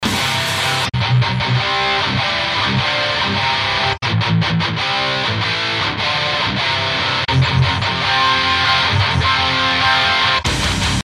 Интереса ради сделал матчинг софта к железному в этом видео, кусок c 4:20 . Воспринимается адекватнее, типа в тот же кабинет+микрофон. Вложения NeuralDSP_match_to_Granophyre.mp3 NeuralDSP_match_to_Granophyre.mp3 431,3 KB · Просмотры: 3.567 Neural_match.png 282,1 KB · Просмотры: 142